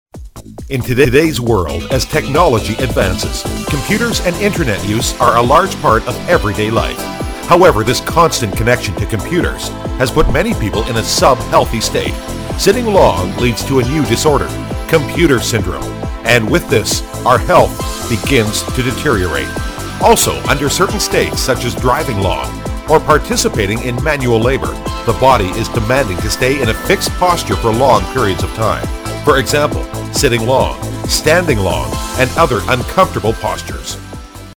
美式英语配音